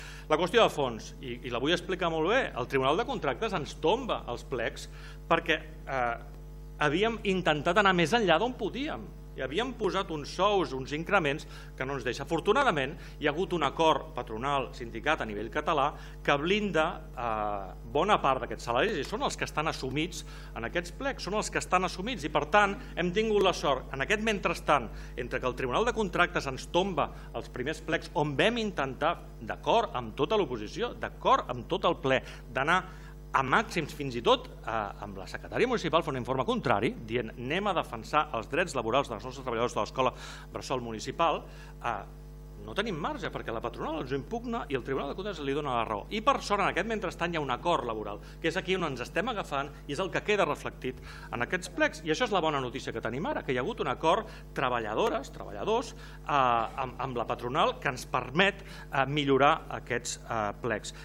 Tot i això, l’alcalde de Tiana, Isaac Salvatierra, va afegir que han pogut reflectir l’acord entre els sindicats i la patronal aconseguit aquest estiu: